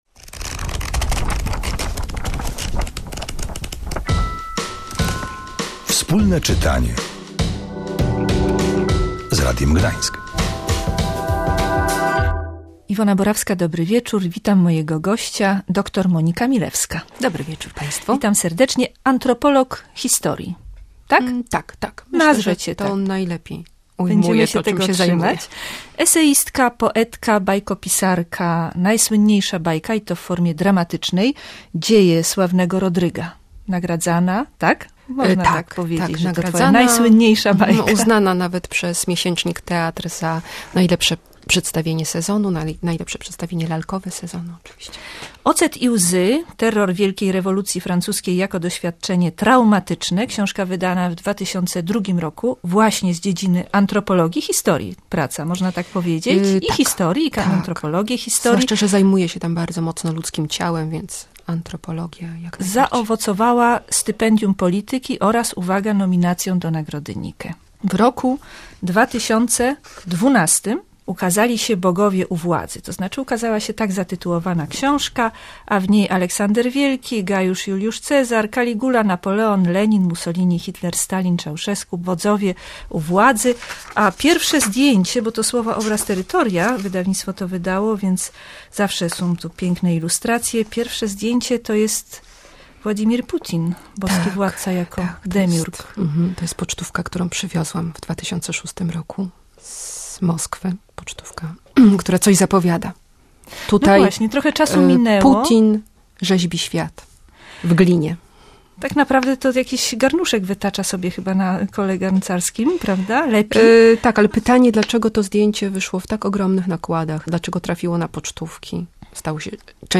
Wyjaśniła, kim są jej bohaterowie i jak wejść do historii. W programie można było też wysłuchać jej wierszy w autorskiej interpretacji.